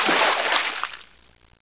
00164_Sound_bucketDump.mp3